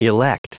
Prononciation du mot elect en anglais (fichier audio)
Prononciation du mot : elect